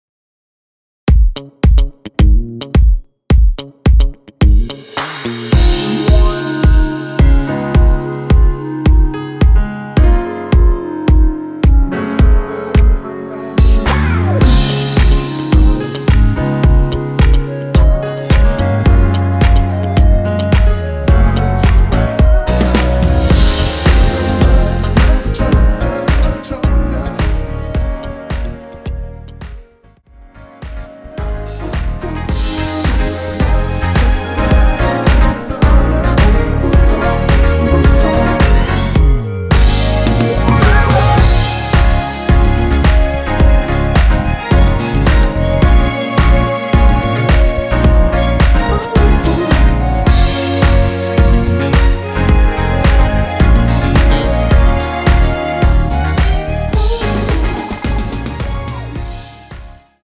원키에서(-3)내린 멜로디와 코러스 포함된 MR입니다.(미리듣기 확인)
Db
앞부분30초, 뒷부분30초씩 편집해서 올려 드리고 있습니다.
중간에 음이 끈어지고 다시 나오는 이유는